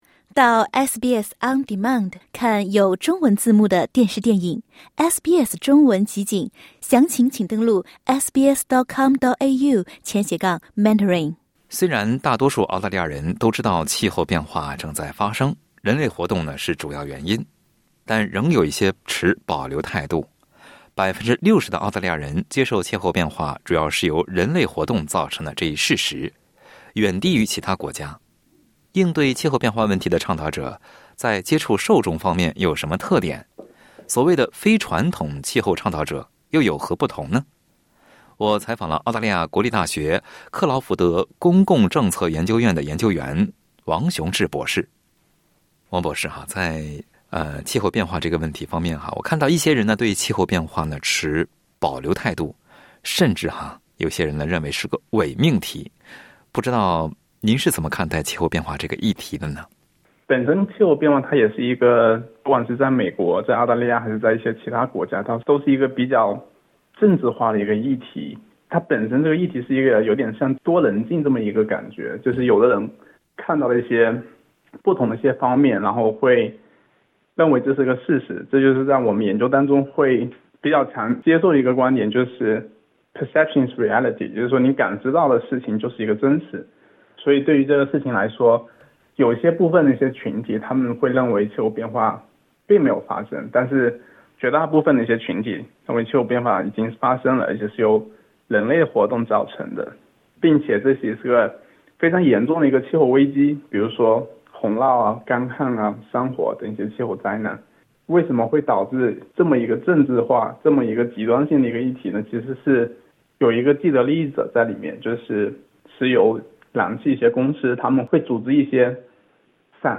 在接受SBS普通话采访时